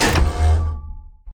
gun-turret-activate-01.ogg